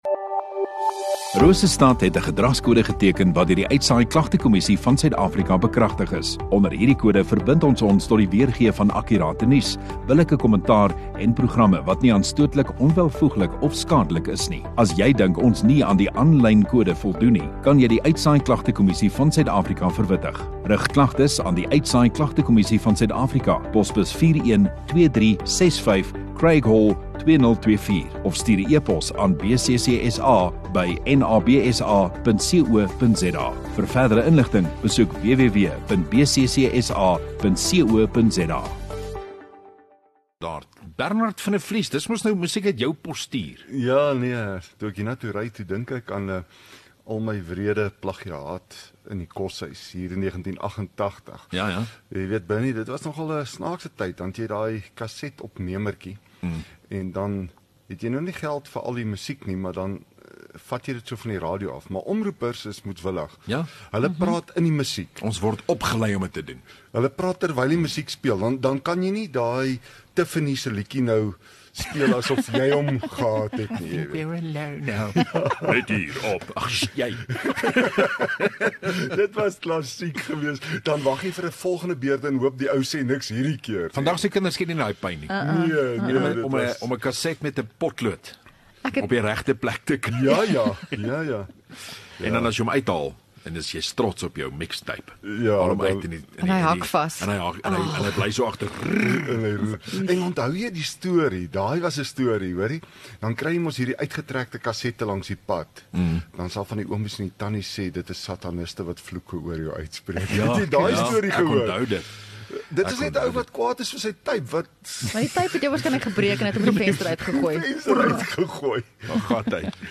23 Apr Dinsdag Oggenddiens